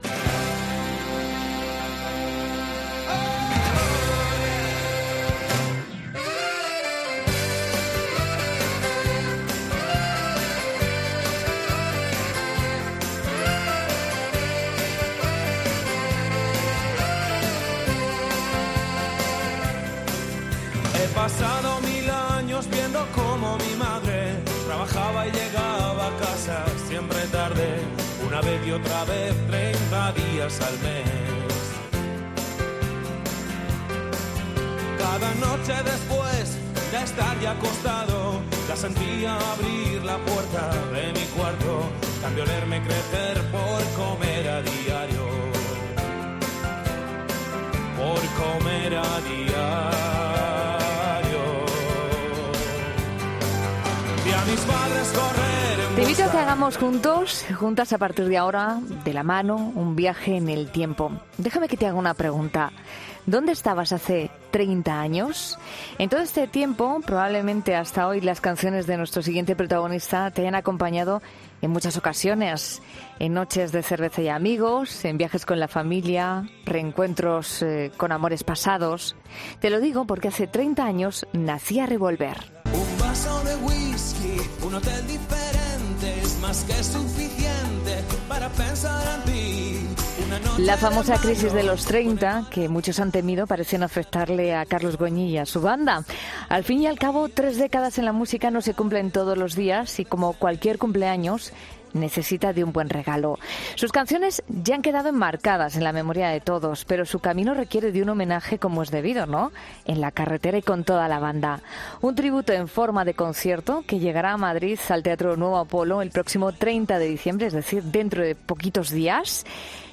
entrevista al músico y cantautor, quien muestra su cara más humana y personal
Carlos Goñi, cantante de Revolver, buenas noches… ¿Cuál es el primer recuerdo que tienes con Revólver hace 30 años?